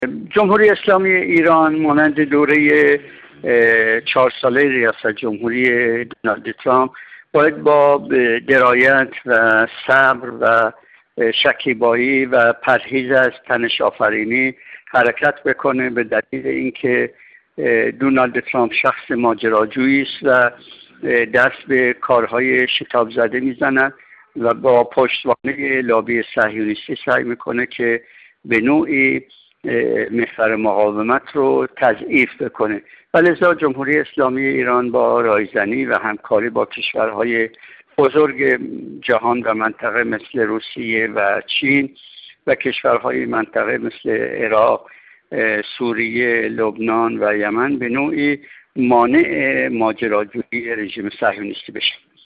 کارشناس مسائل منطقه
گفت‌وگو